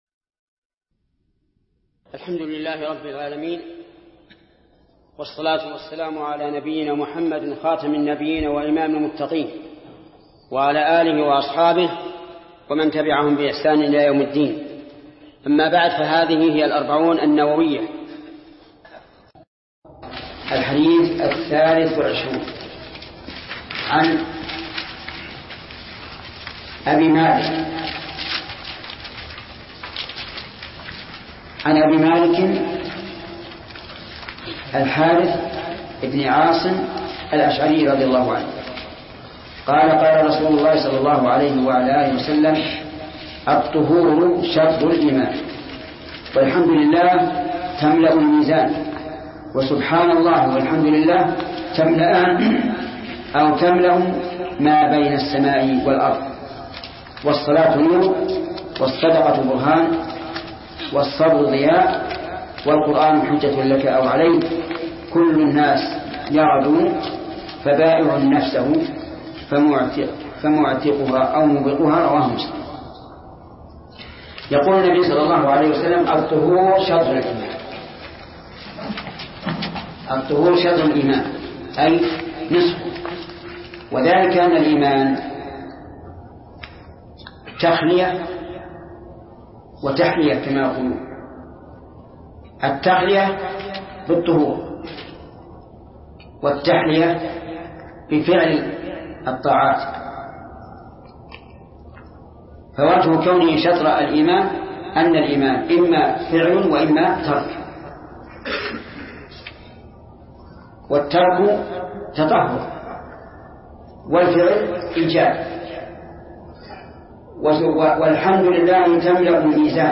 الدرس السابع عشر : من قوله: الحديث الثالث والعشرون، إلى: نهاية الحديث الثالث والعشرون.